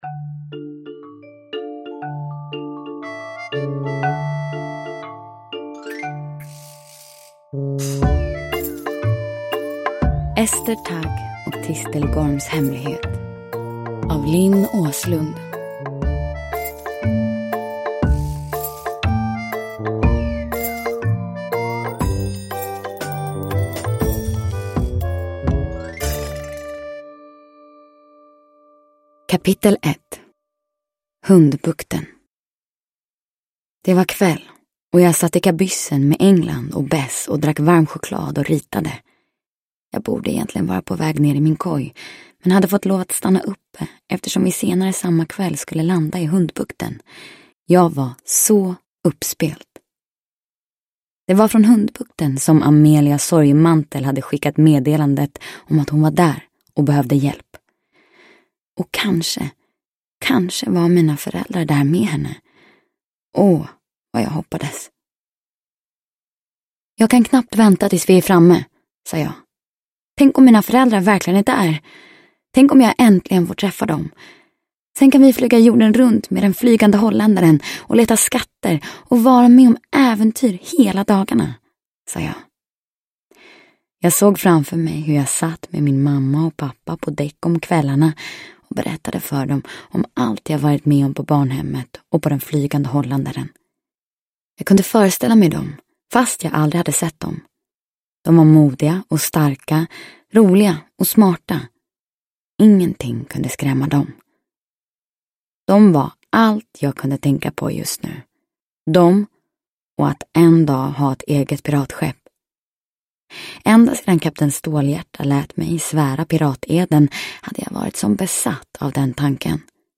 Ester Tagg och Tistelgorms hemlighet – Ljudbok
Uppläsare: Hedda Stiernstedt